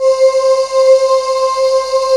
BREATH VOX 1.wav